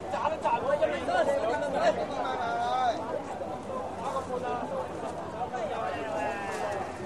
Crowd Hong Kong, Chinese Men